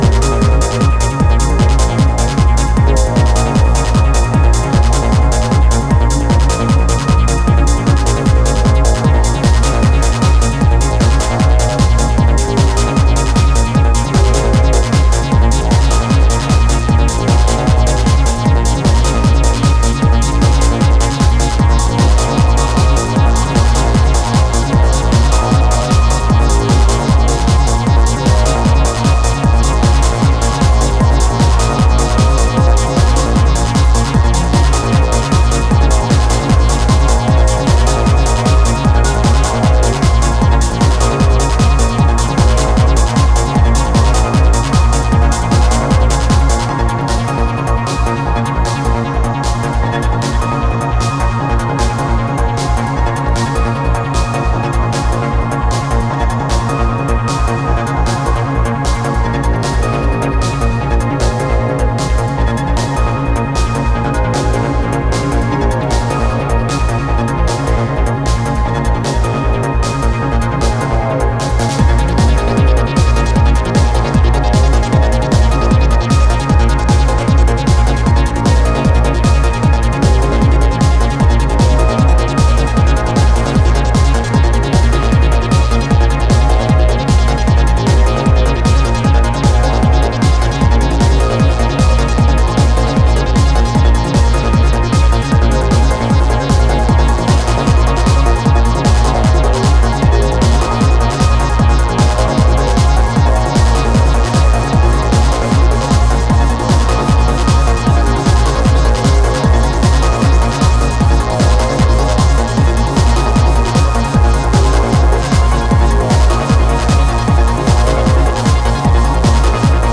Genre Ambient , Trance